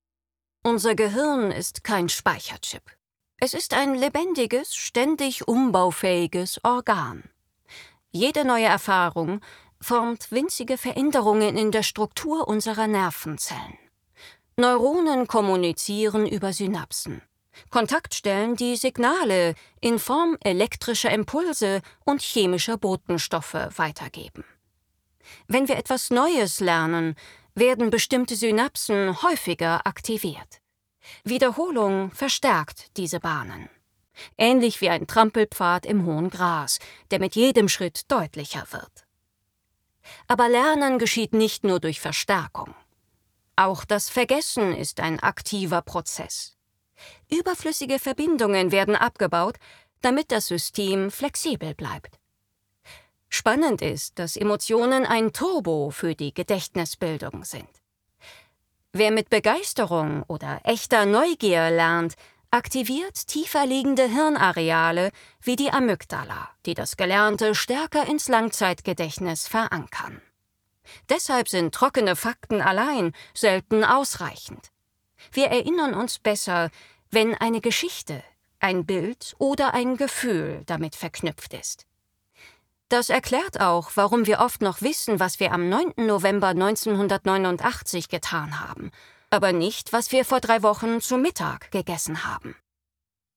Audio Guides
My delivery is natural, engaging, and precise — ideal for brands that value credibility, emotion, and clarity.
🎧 Voice style: Warm · Modern · Confident · Engaging · Trustworthy · Natural